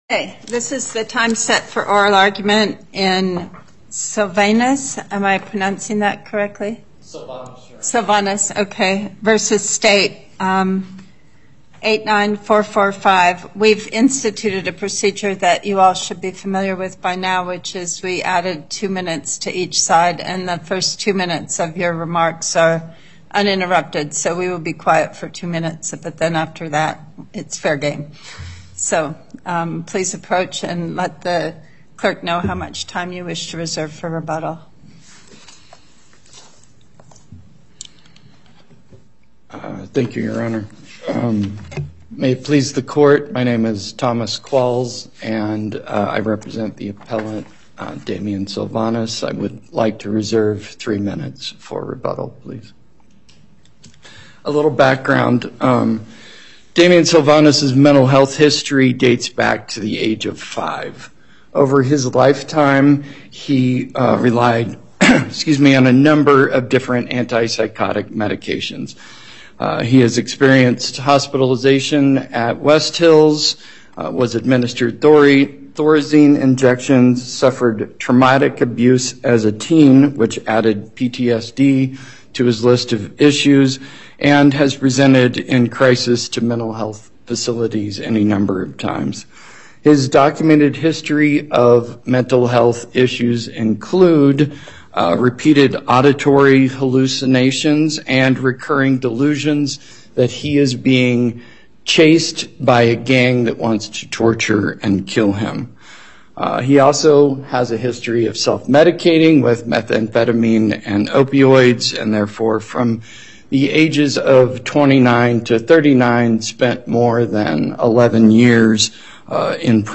Before Panel B25 Justice Pickering presiding Appearances
on behalf of Respondent